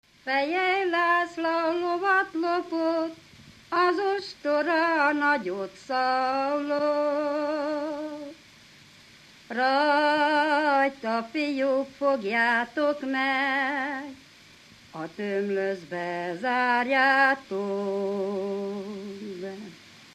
Alföld - Szatmár vm. - Szamosdara
ének
Műfaj: Ballada
Stílus: 4. Sirató stílusú dallamok
Szótagszám: 8.8.8.8
Kadencia: 6 (5) b3 1